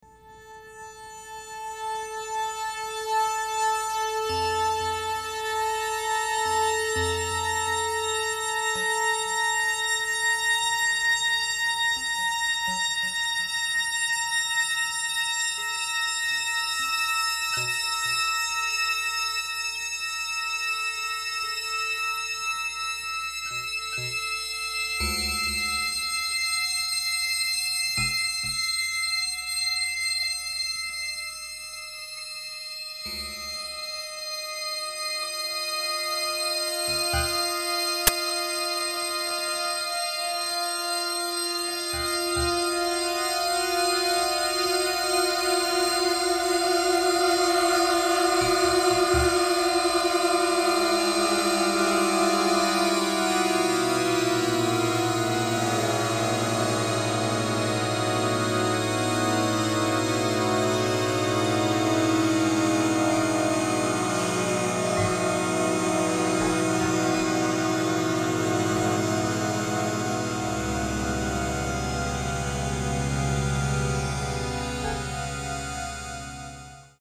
Free Flight (electronics)
The 6th and 7th pitches of P0 are F and F#.
Timbres were used singularly to form each of six tracks. Two timbres are modified piano sounds and one is a modified organ sound. The timbres were brightened and initial/final decays of the envelopes were elongated. Three shorter sounds (glockenspiel, string pizzicato, harp) are altered timbrally by modifying the waveshape and tone color. Six tracks were recorded individually on four separate channels at different tempi. The final two-channel performance is a mixdown of the four-channel version.